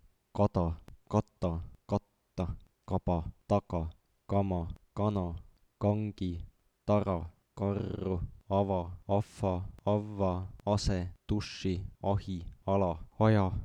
Lühike alveolaarne klusiil /t/ sõnas *kada*.
Pikk alveolaarne klusiil /t/ sõnas *kata*.
Ülipikk alveolaarne klusiil /t/ sõnas *katta*.
konsonandid.wav